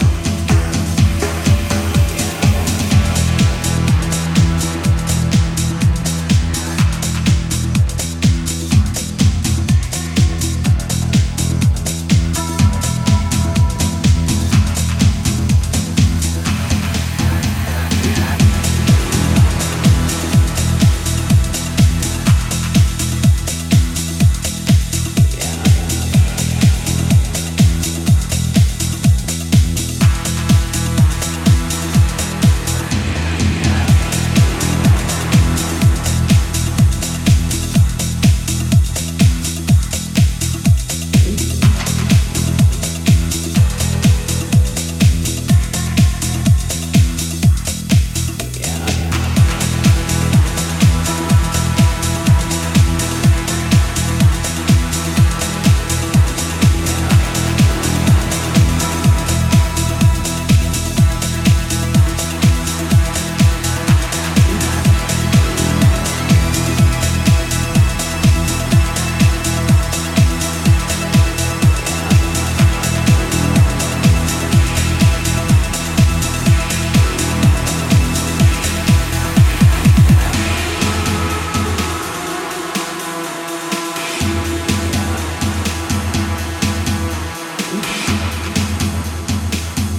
パンチの効いたピークタイム仕様のハウス路線をアッパーに行き過ぎないさじ加減で展開していく
80's〜90'sフィーリングをセンス良く現代的に取り入れながらフロアを熱いエナジーで満たしていく大推薦盤です！